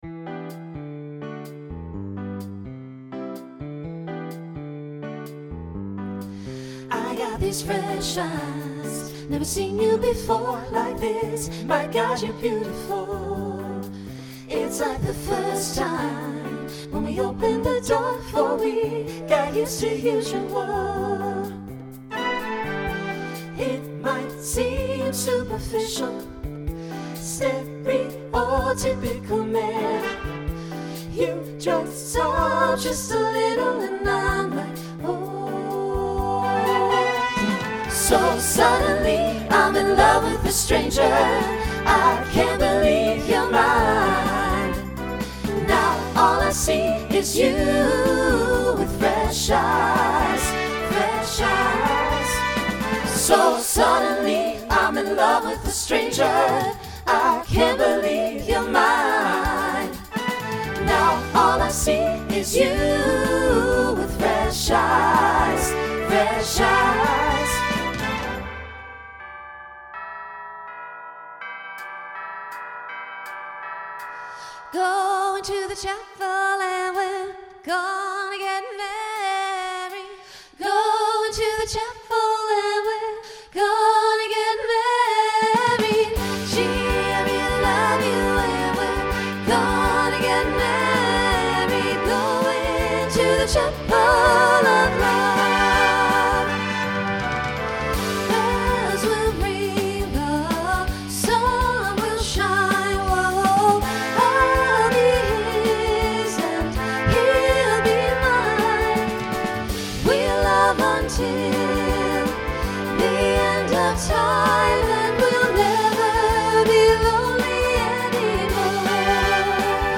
(SATB)
Voicing Mixed Instrumental combo Genre Pop/Dance , Rock
Mid-tempo